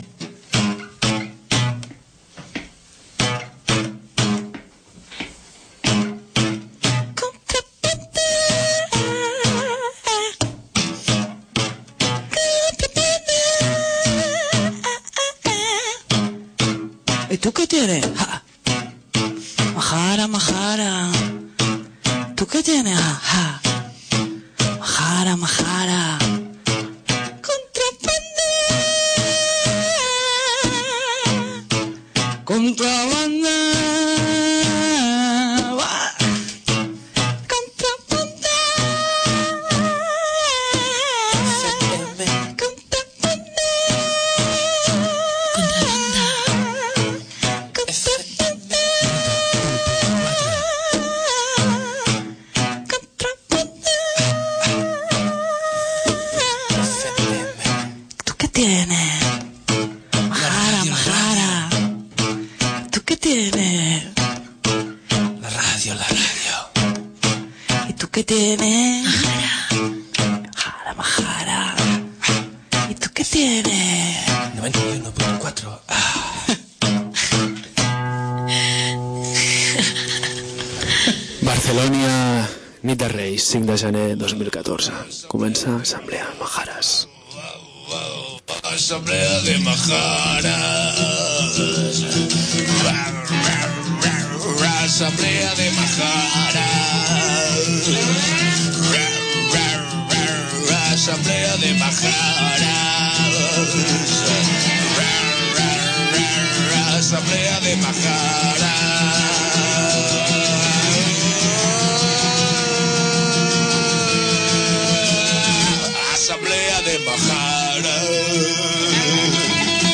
Escoltem l’entrevista a membres de Radio Mutant d’Alacant enregistrada durant la II Trobada de Ràdios Lliures del País Valencià.